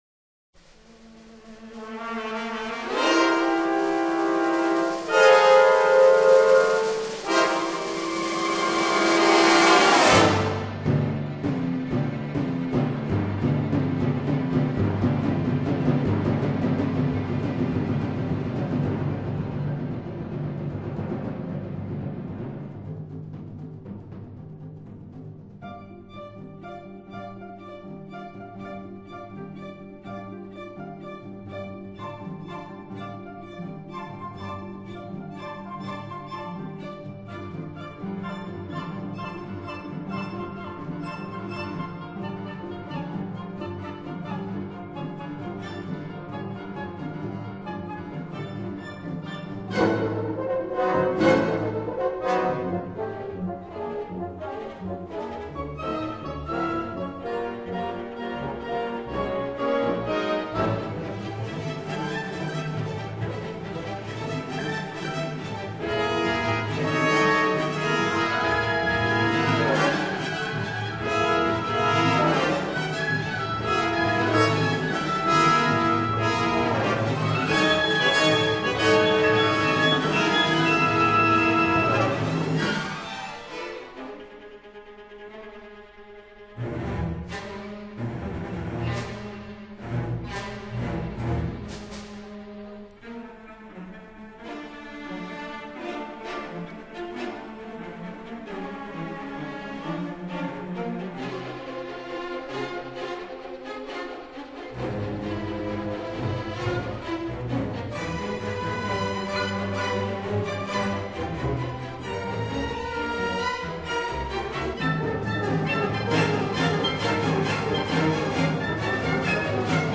这是一段具有终曲性质的音乐。